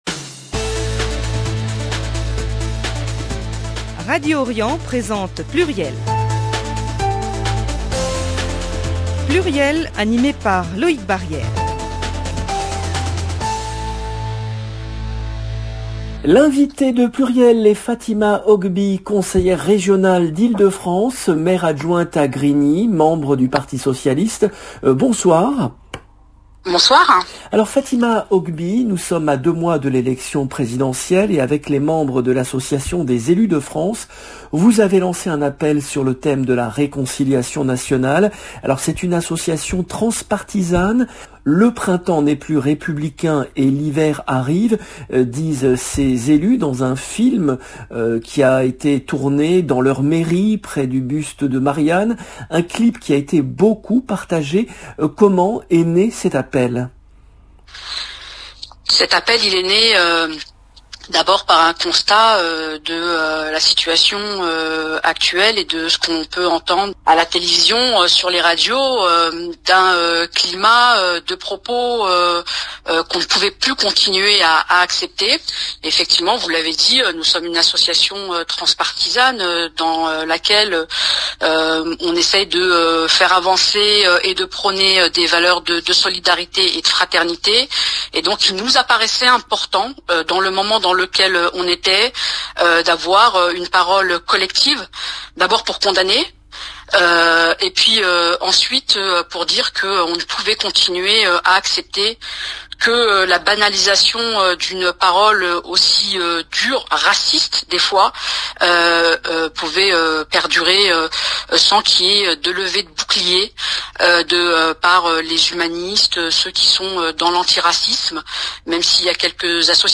Fatima Ogbi, conseillère régionale PS d'Ile-de-France